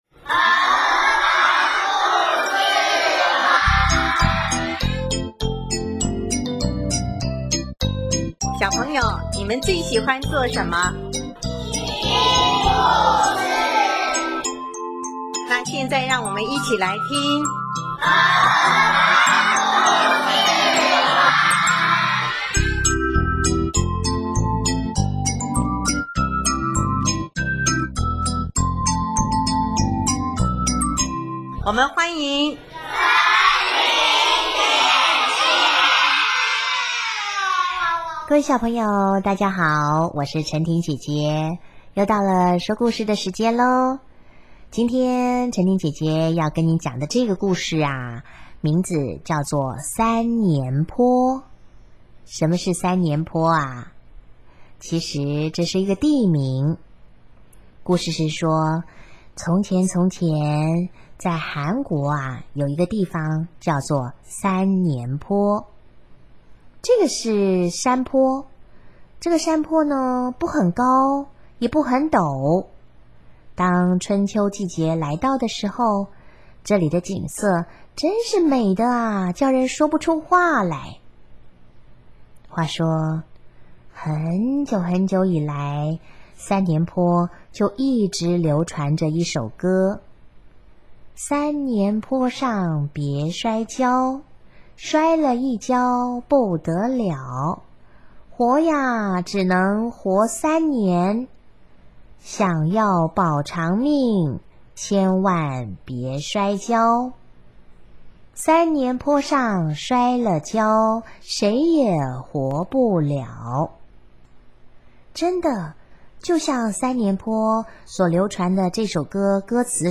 【儿童故事】